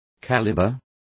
Complete with pronunciation of the translation of caliber.